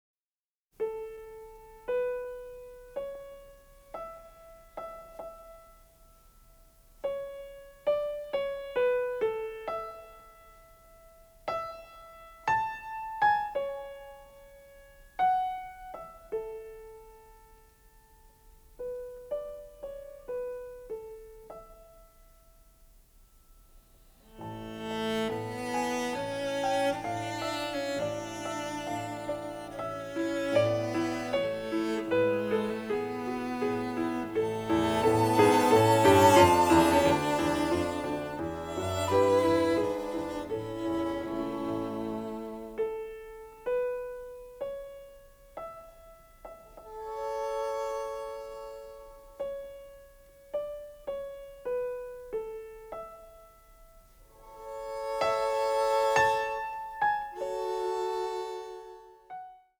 giallo score
sophisticated avant-garde sound